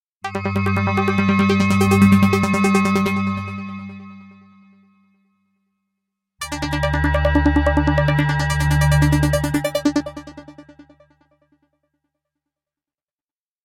Звуки джинглов
• Качество: высокое